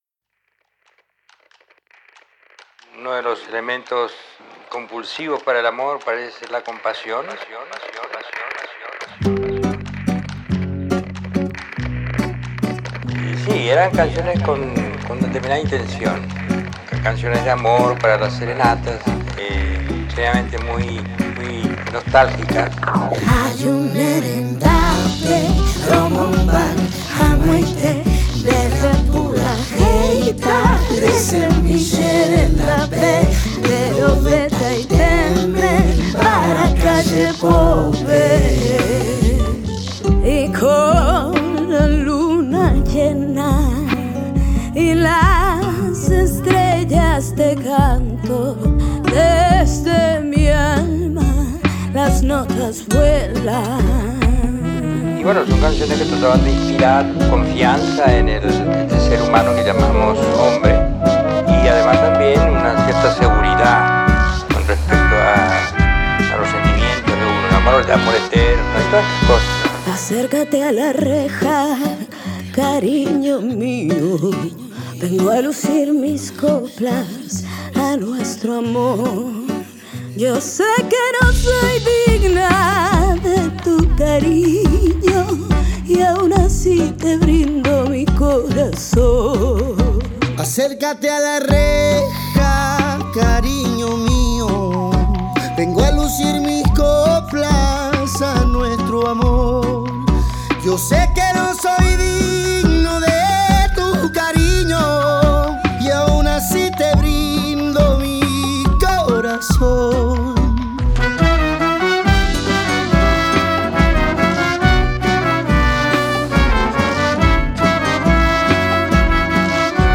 Es una propuesta de folclore fusión con ritmo de vals